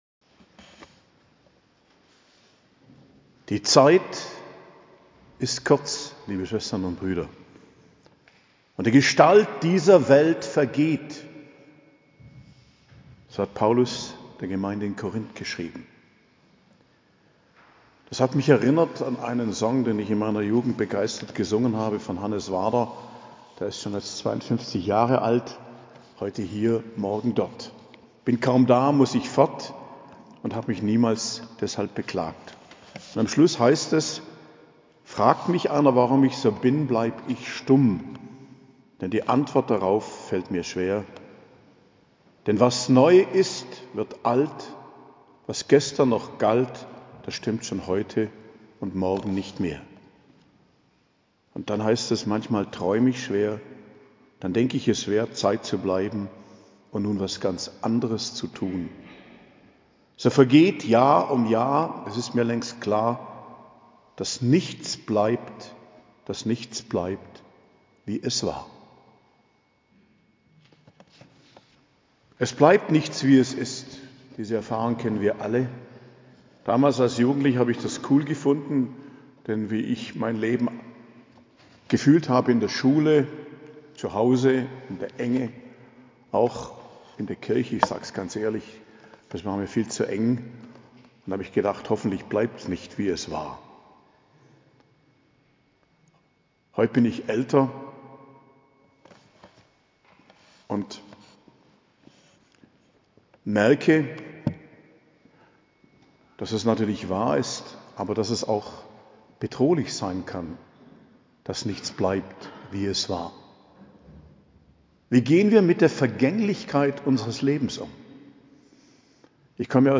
Predigt zum 3. Sonntag i.J., 21.01.2024